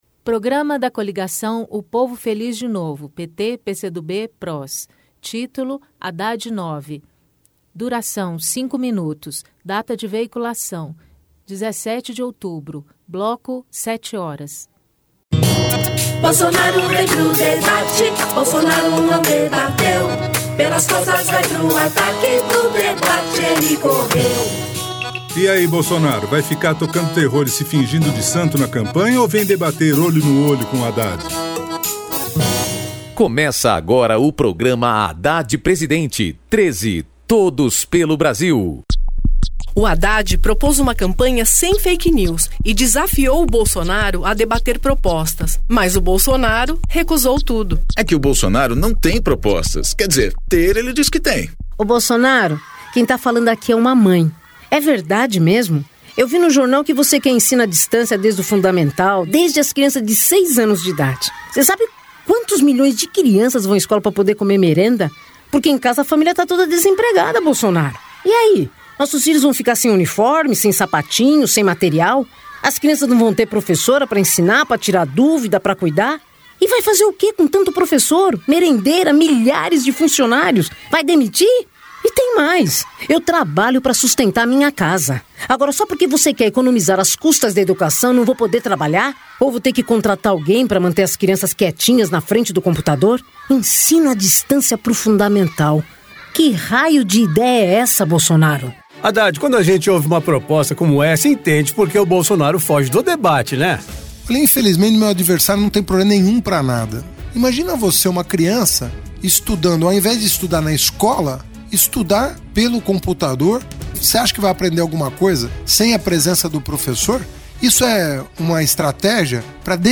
Gênero documentaldocumento sonoro
Descrição Programa de rádio da campanha de 2018 (edição 39), 2º Turno, 17/10/2018, bloco 7hrs.